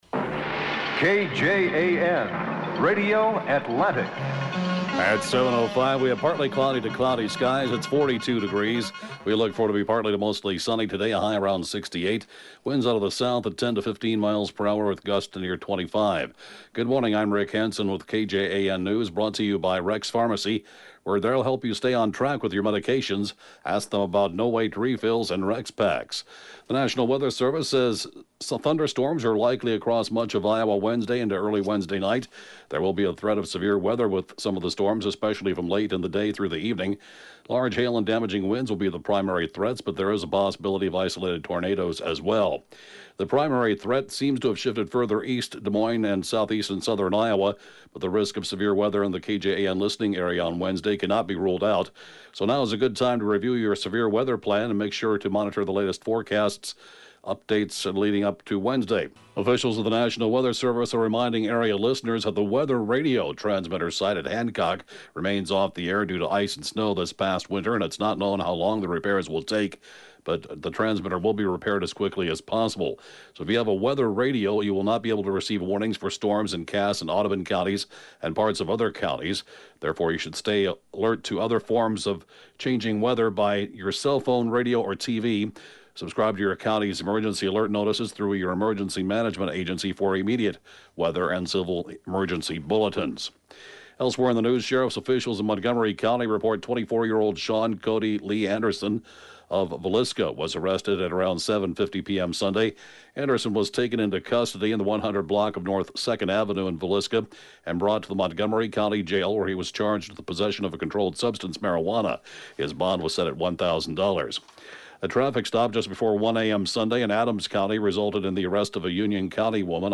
(Podcast) KJAN Morning News & Funeral report, 4/15/19